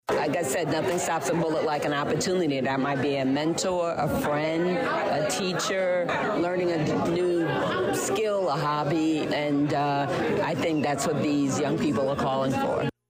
(ABOVE) Congresswoman Robin Kelly leads a roundtable discussion with Danville High School students focused on gun violence.